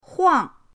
huang4.mp3